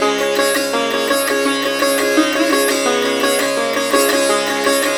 SITAR GRV 18.wav